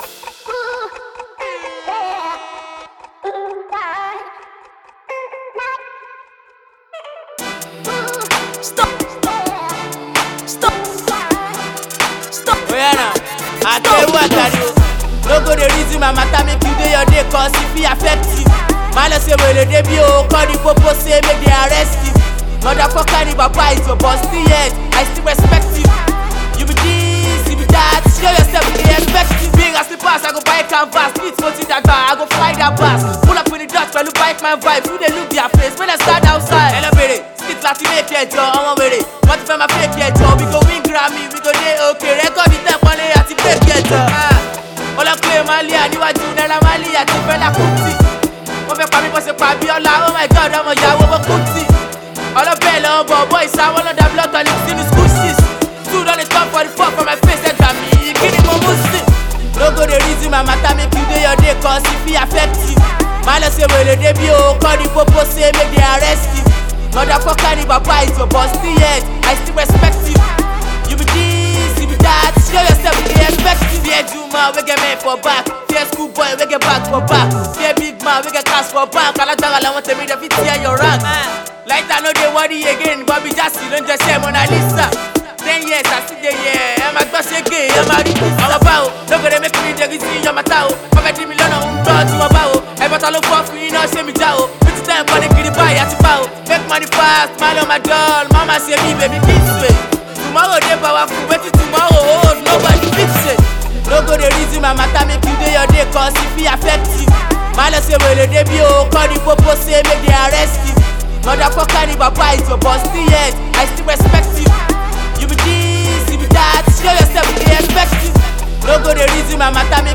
hilarious freestyle song